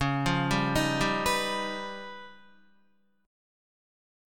Db9b5 Chord
Listen to Db9b5 strummed